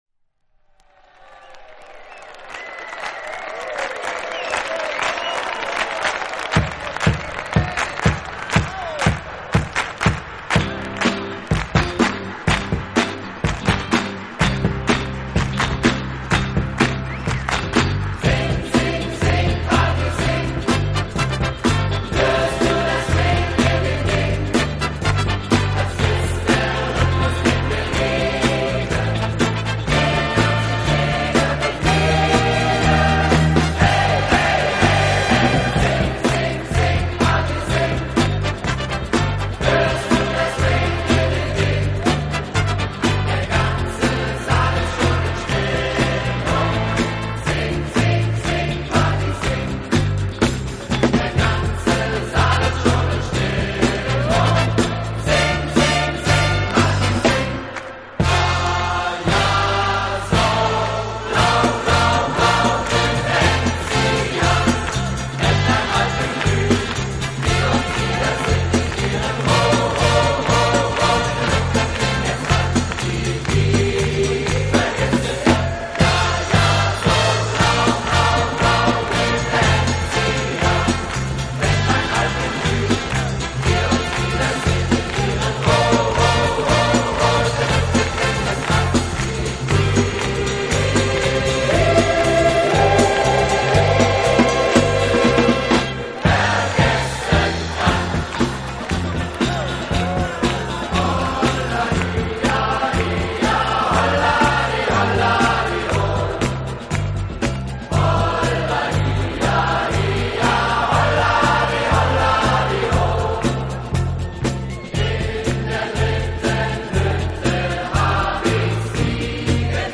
音乐类型：pop/ easy listening